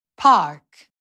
How-to-pronounce-PARK-in-American-English_cut_1sec.mp3